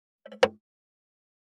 569魚切る,肉切りナイフ,
効果音厨房/台所/レストラン/kitchen食器食材